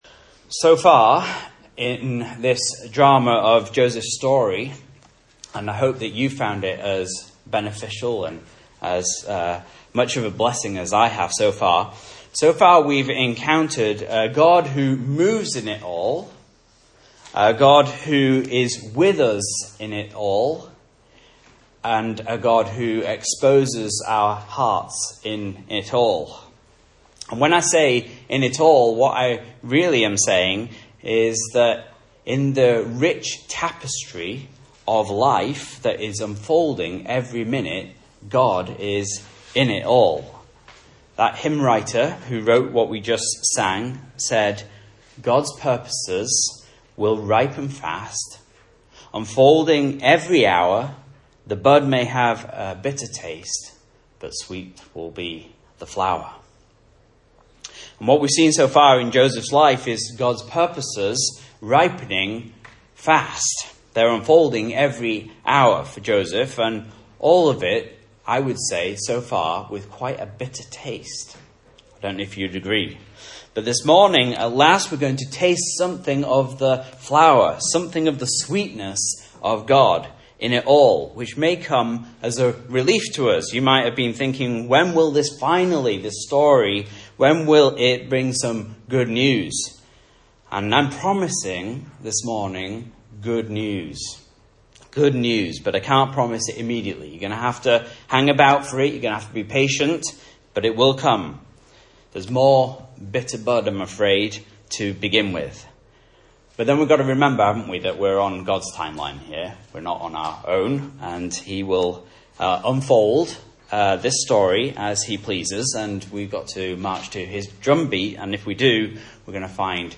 Message Scripture: Genesis 43-45 | Listen